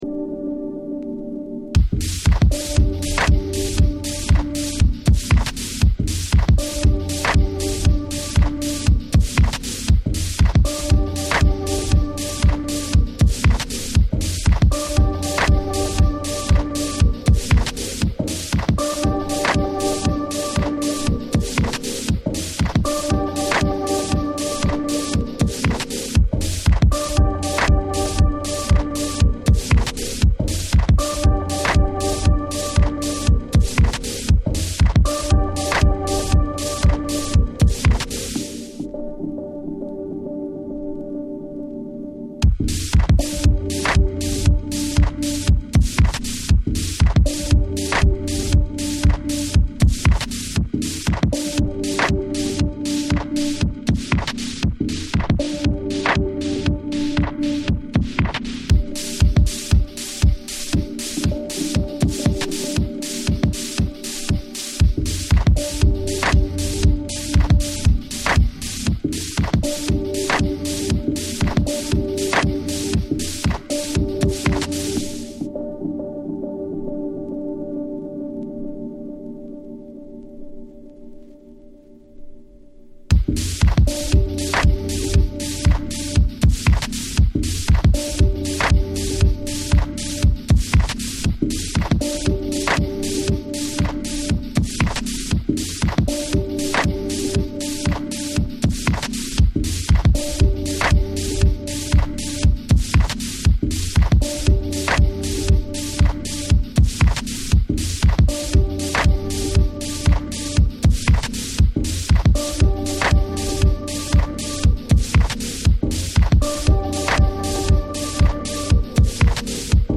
steady 4/4 tracks